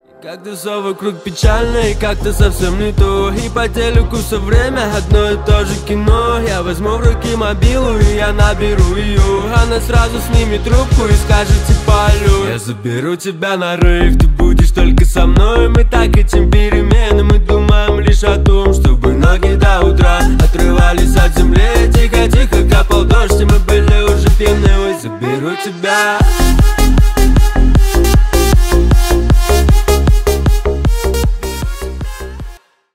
Поп Музыка # Танцевальные
весёлые